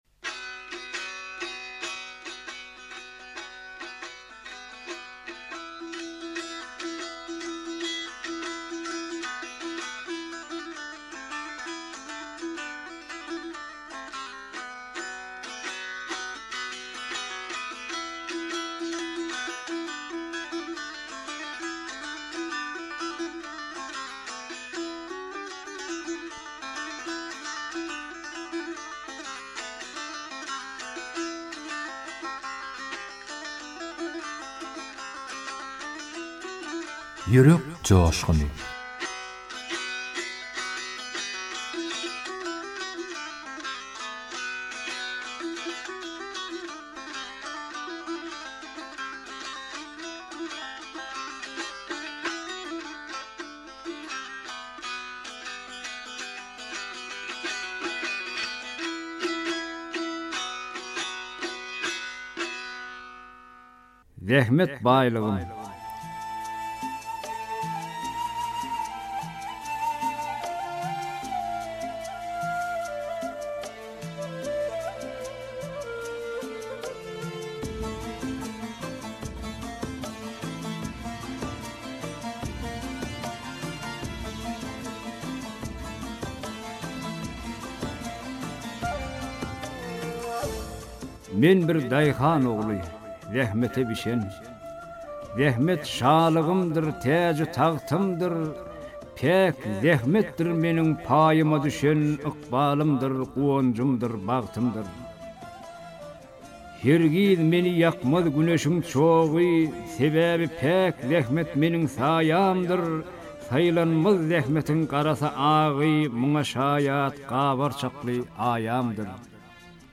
turkmen goşgy owaz